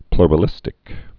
(ploŏrə-lĭstĭk)